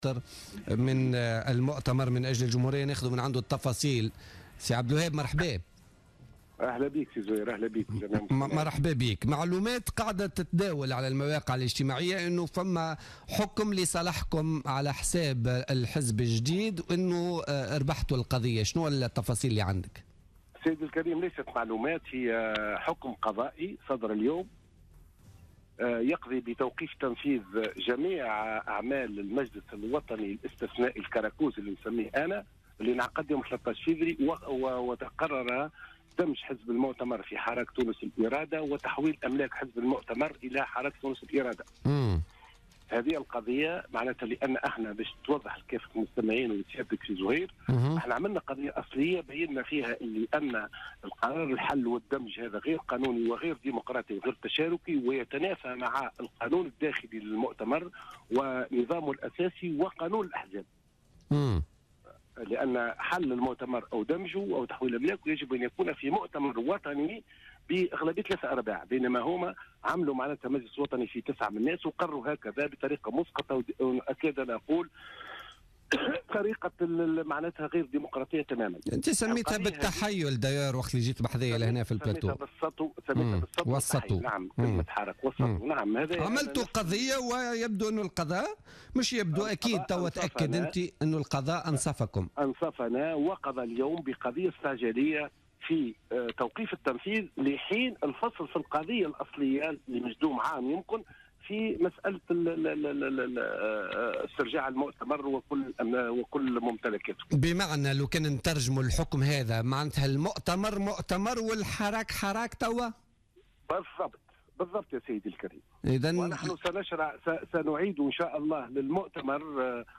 أكد القيادي بحزب المؤتمر من أجل الجمهورية عبد الوهاب معطر في مداخلة له في بوليتيكا اليوم الجمعة 4 مارس 2016 أن الدائرة الإستعجالية بإبتدائية تونس أصدرت اليوم حكما يقضي بإيقاف جميع القرارات الصادرة عن المجلس الوطني الاستثنائي المنعقد بتاريخ 13 فيفري 2016 والذي تقرر خلاله دمج حزب المؤتمر مع حزب حراك تونس الإرادة وتحويل أمواله إلى الحزب الجديد .